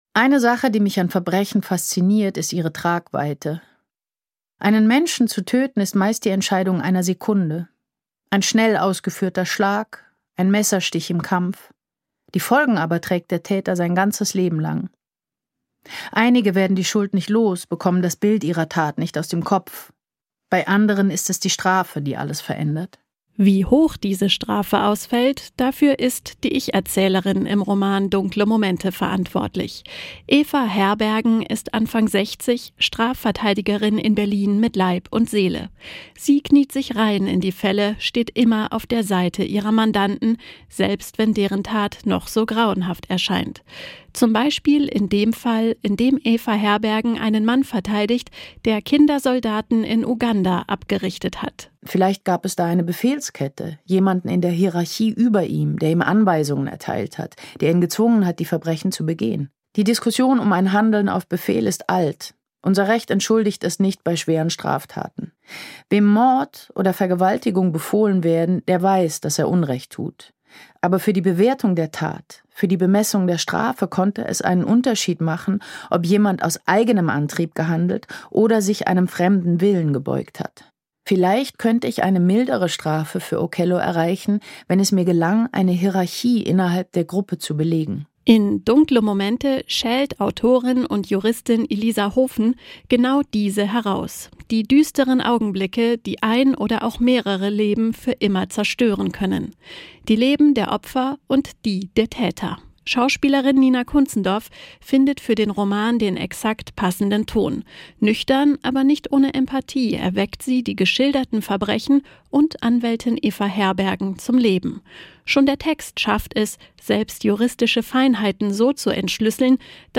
dunkle-momente-packendes-hoerbuch-mit-nina-kunzendorf.m.mp3